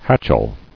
[hatch·el]